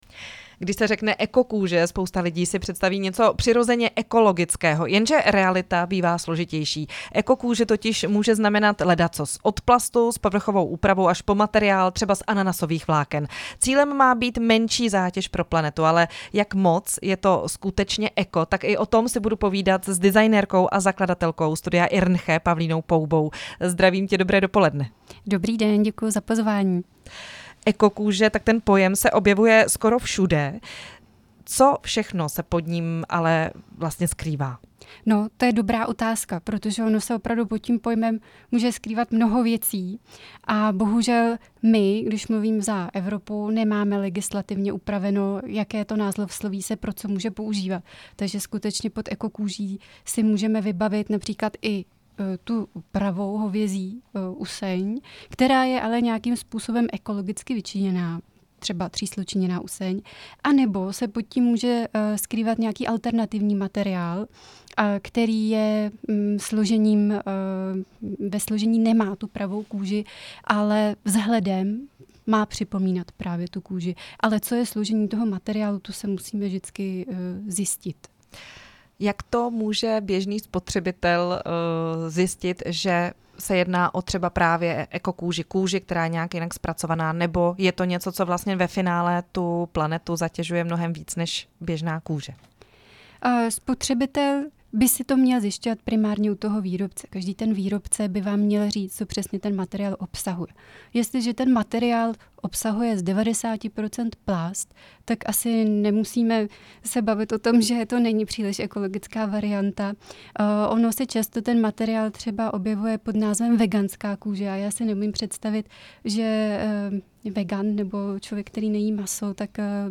První část rozhovoru